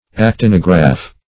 Search Result for " actinograph" : The Collaborative International Dictionary of English v.0.48: Actinograph \Ac*tin"o*graph\, n. [Gr.